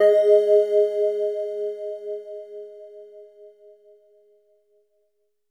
LEAD G#3.wav